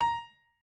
piano2_3.ogg